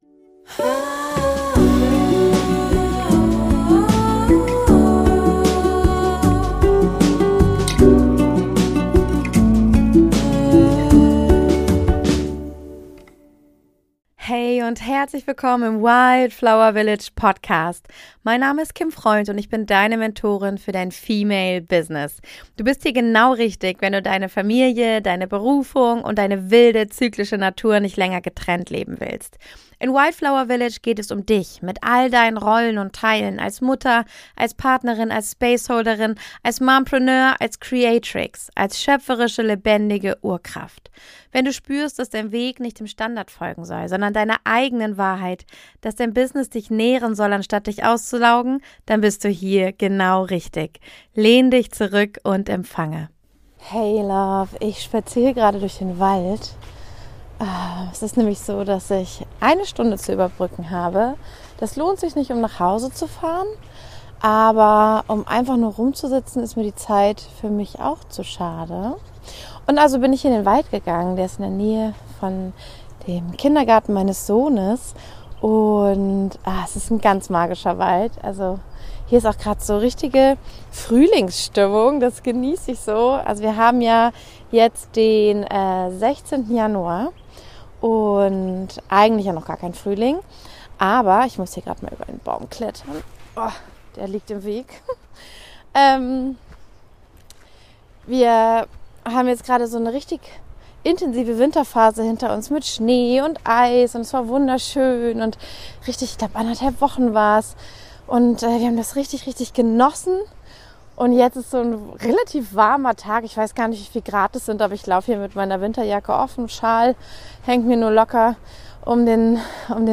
In dieser ganz persönlichen Diary-Folge nehme ich dich mit auf einen Waldspaziergang durch meine aktuelle Winter-Realität.